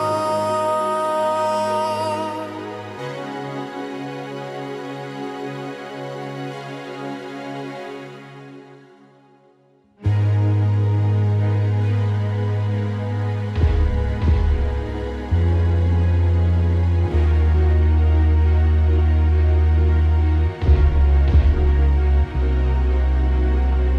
Minus Guitars Rock 4:35 Buy £1.50